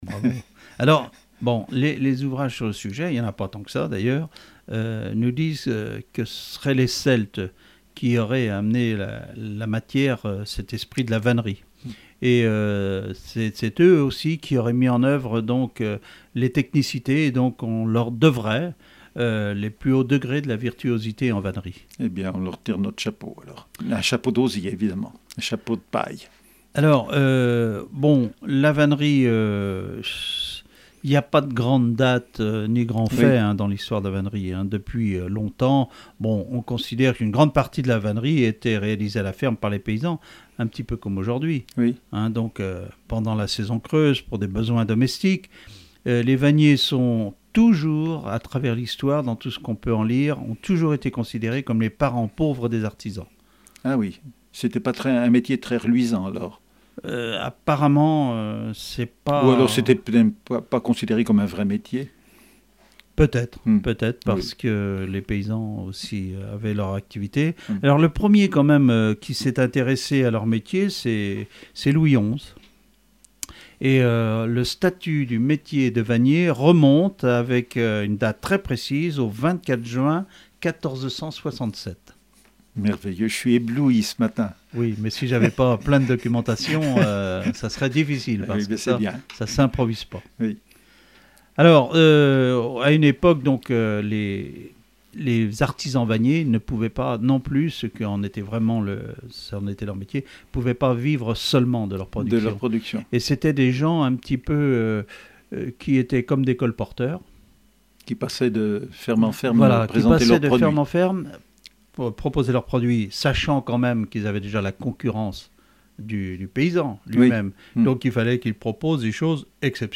La vannerie extrait d'une émission de radio
Catégorie Témoignage